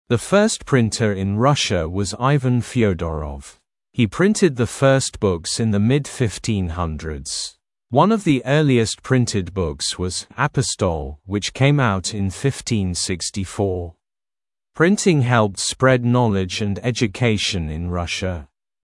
Произношение:
[Зэ фёст принтэр ин Раша уоз Иван Фёдоров. Хи принтэд зэ фёст букс ин зэ мид фифтин хандридс. Уан ов зи ёрлиэст принтэд букс воз «Апостол», уич кейм аут ин фифтин сиксти фор. Принтинг хэлпт спред нолидж энд эджюкейшн ин Раша].